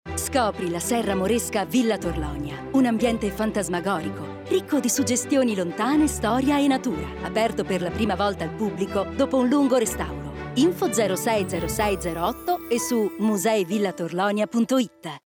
Corporate Videos
Her voice is sincere, soft, warm,versatile, friendly, natural, smooth.
ContraltoMezzo-Soprano
SincereSoftWarmVersatileFriendlyNaturalSmooth